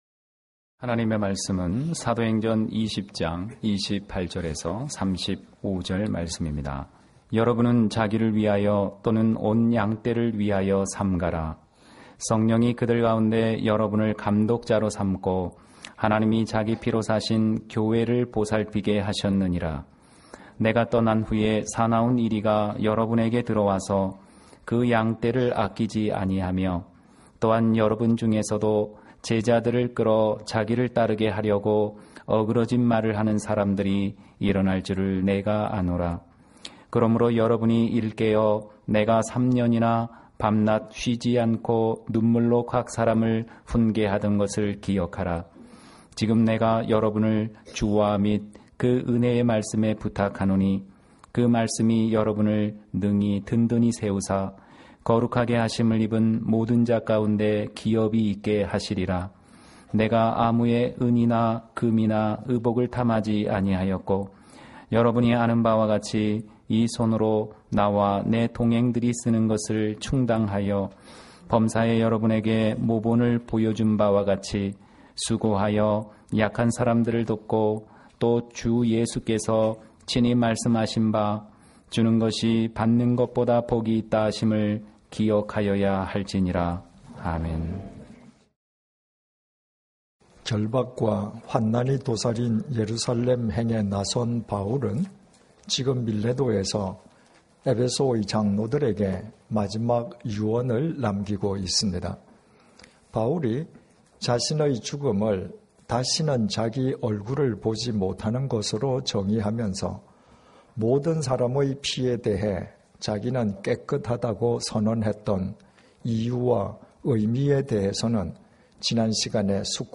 예배 주일예배